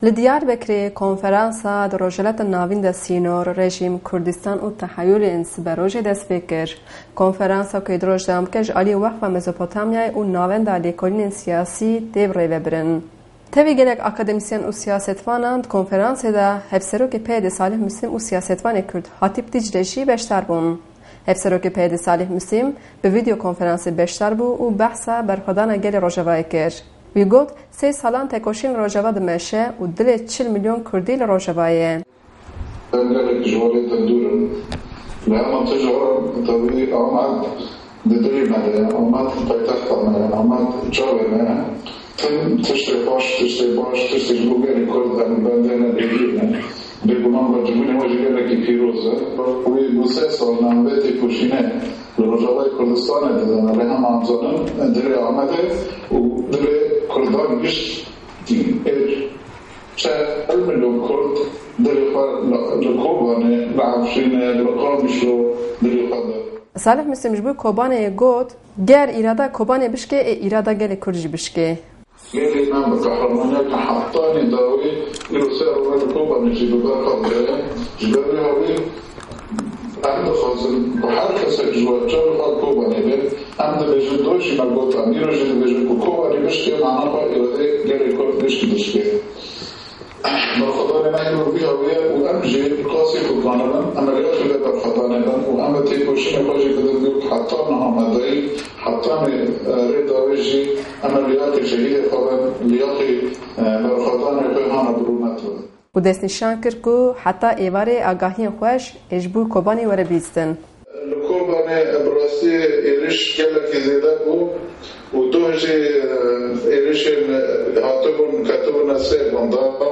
Hevserokê PYD’ê Salih Mislim bi rêya Skype, Hatîp Dîcle jî wek mêvanê rûmetê beşdarê konferasê bûn.